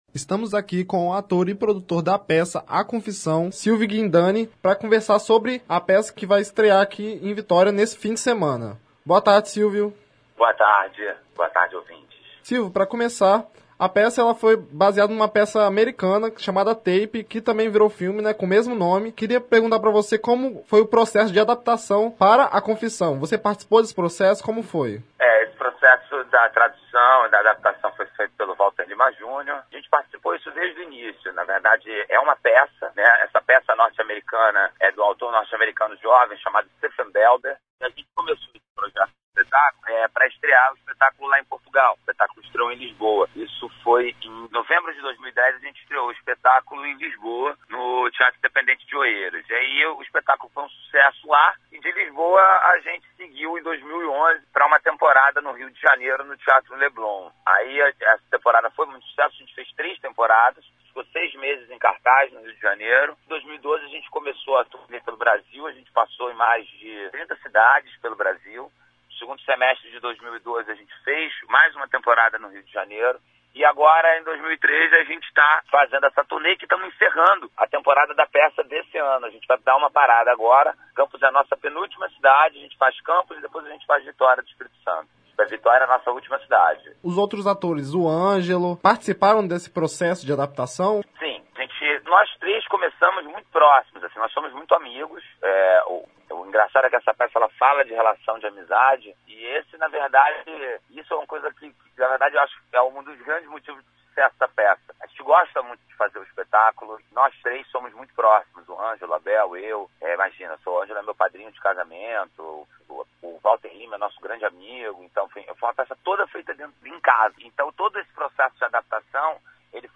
Entrevista com Silvio Guindane